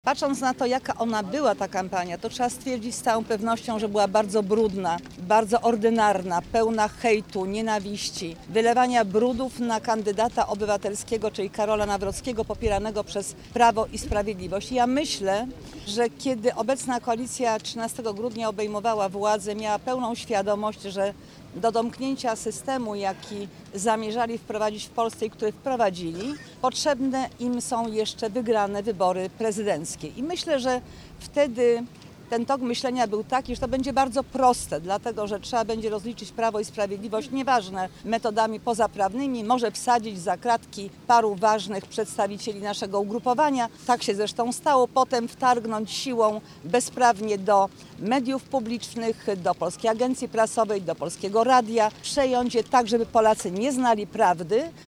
-Staramy się nie zmarnować ani godziny, być z ludźmi i zachęcać ich do głosowania. To była brudna kampania, mówi Elżbieta Witek – była marszałek Sejmu.